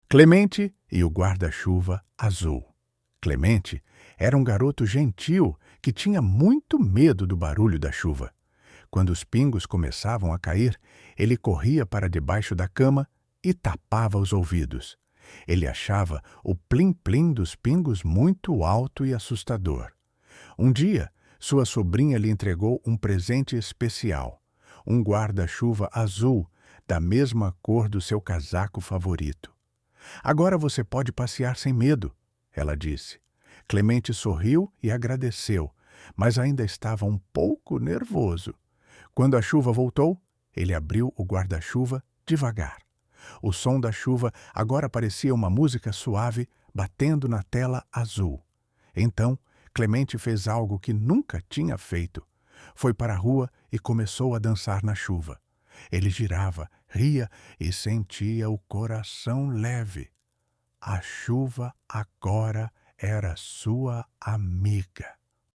Áudios de todas as histórias